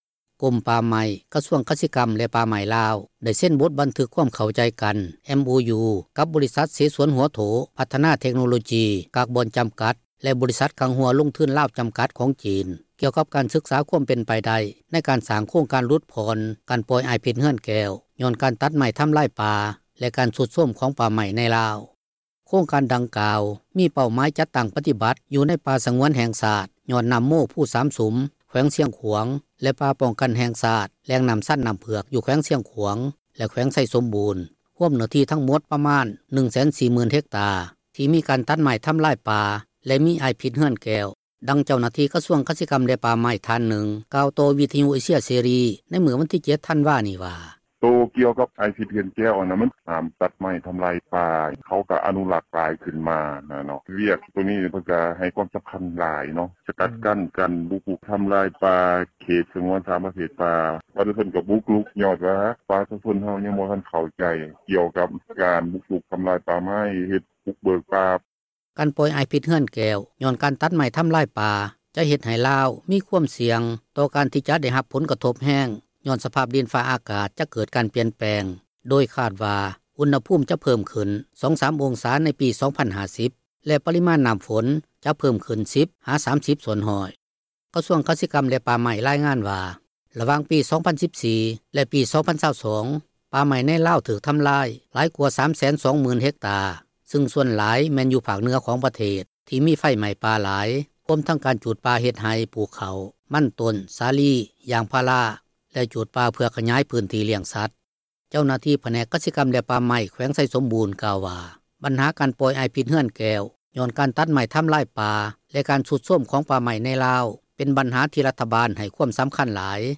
ດັ່ງເຈົ້າໜ້າທີ່ກະຊວງກະສິກັມ ແລະປ່າໄມ້ ທ່ານນຶ່ງກ່າວຕໍ່ ວິທຍຸເອເຊັຽເສຣີ ໃນມື້ວັນທີ 7 ທັນວານີ້ວ່າ: